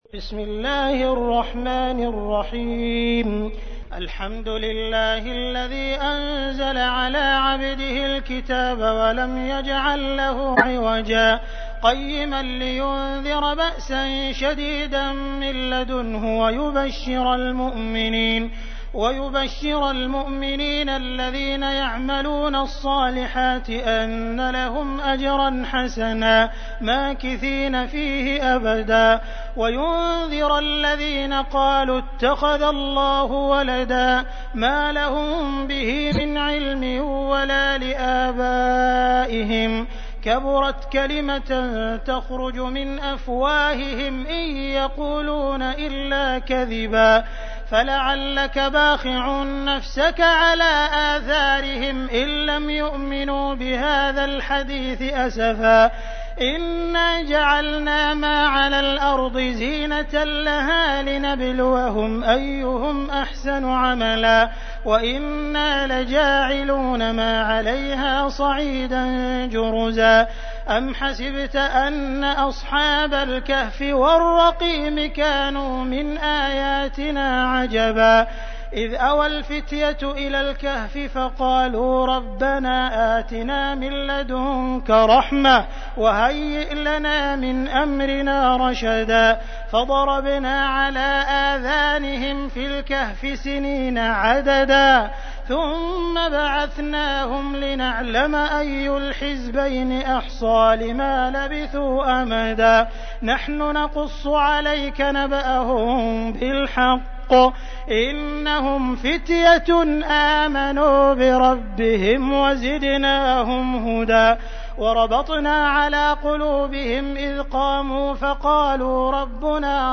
تحميل : 18. سورة الكهف / القارئ عبد الرحمن السديس / القرآن الكريم / موقع يا حسين
موقع يا حسين : القرآن الكريم 18.